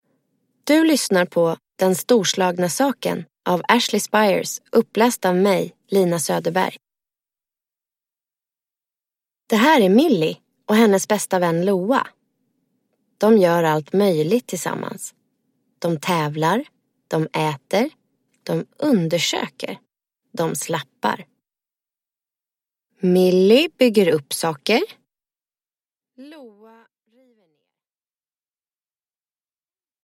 Den storslagna saken – Ljudbok